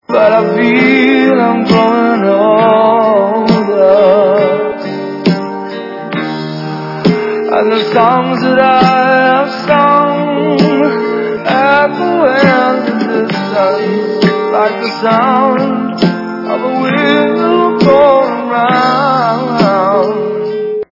При заказе вы получаете реалтон без искажений.